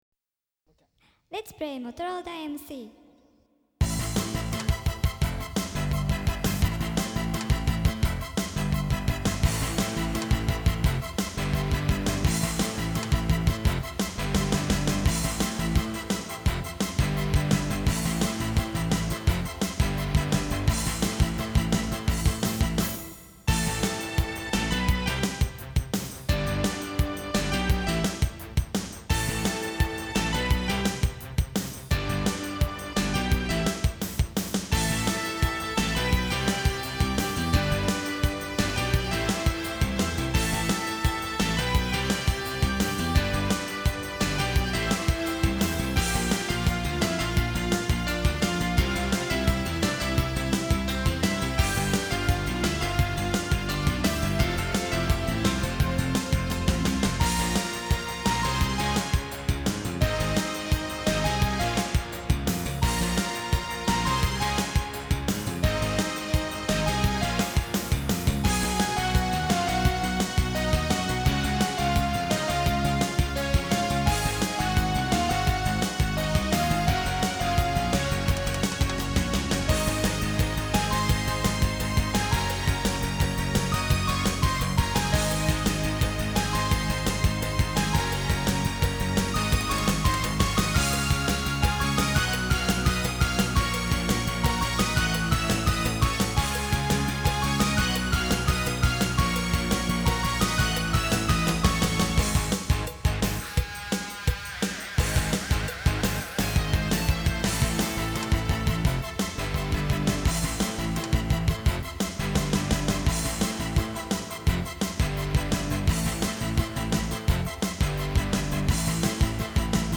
Cowbell @ 1:19 & 2:46
Subtle, but it is there.